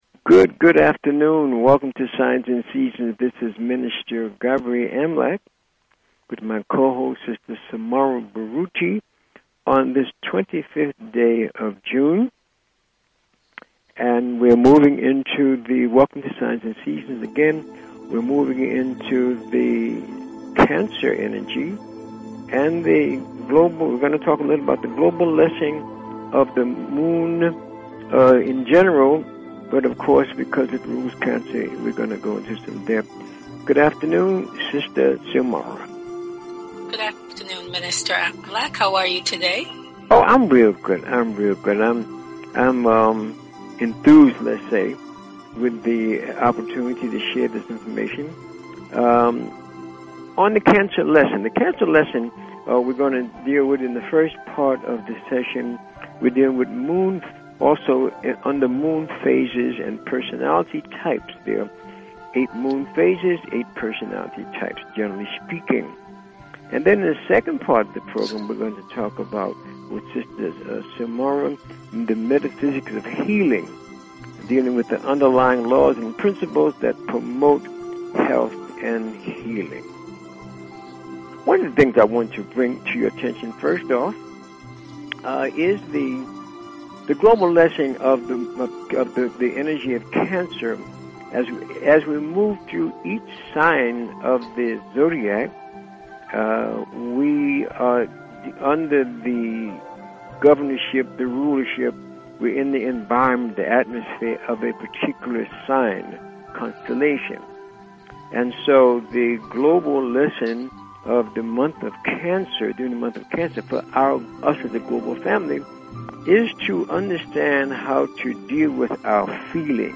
Talk Show Episode, Audio Podcast, Signs_and_Seasons and Courtesy of BBS Radio on , show guests , about , categorized as
The Primary objectives of this broadcast are: To ELIMINATE the fearful and superstitious attitudes that many peple have towards Astrology and related "Metaphysical" teachings by providing information on the constructive uses of these ancient sciences. And to demonstrate the practical value of Astrology and Numerology by giving on air callers FREE mini readings of their personal Astro-numerica energy profiles.